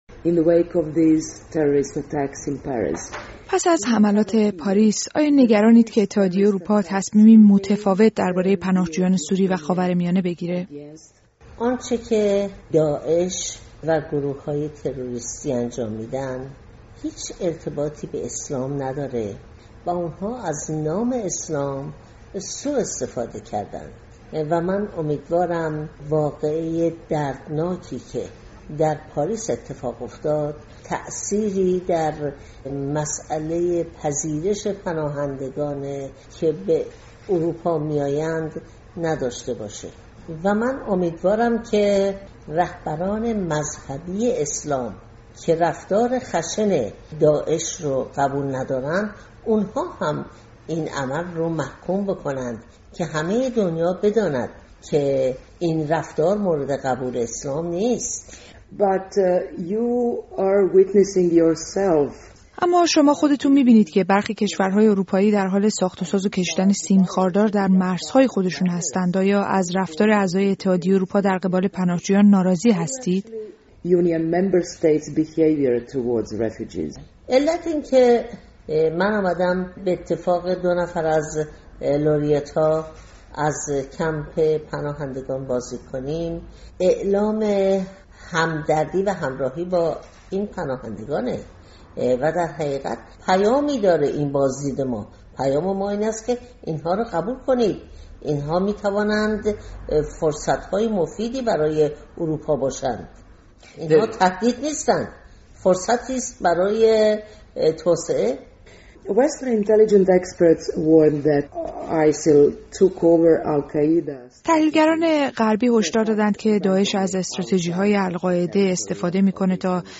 مصاحبه شيرين عبادی، برنده جايزه صلح نوبل، در بلگراد با بخش بالکان رادیو اروپای آزاد/رادیو آزادی